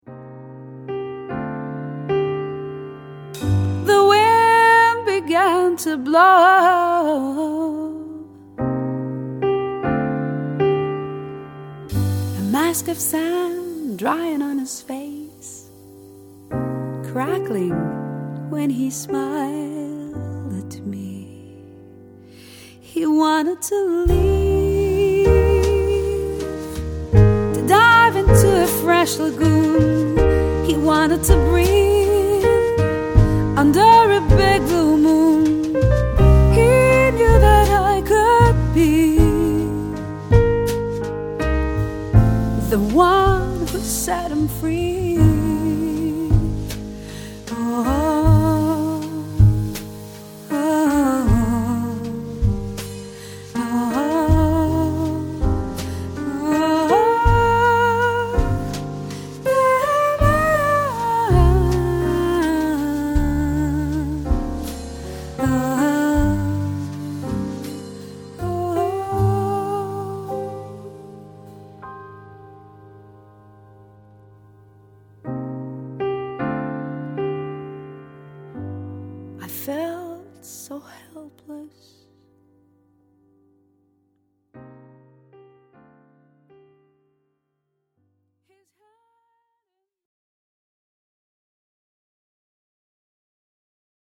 bassist
drummer
crystal clear voice